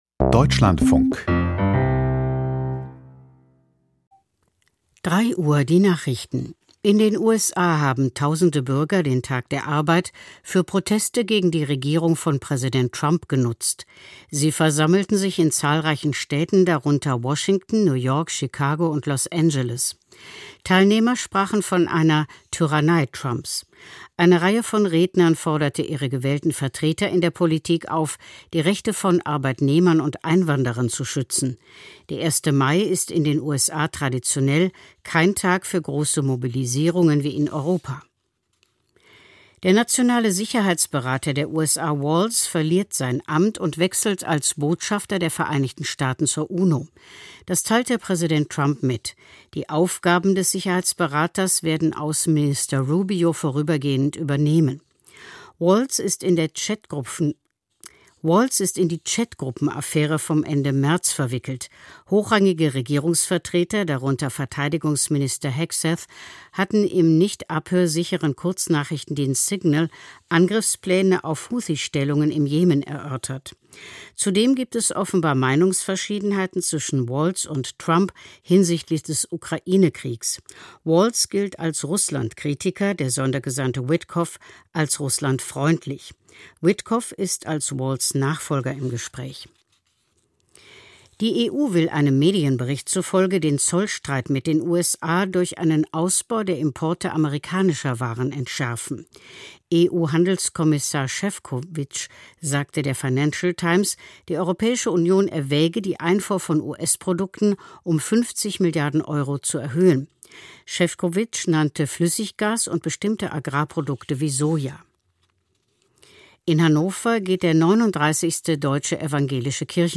Die Deutschlandfunk-Nachrichten vom 02.05.2025, 03:00 Uhr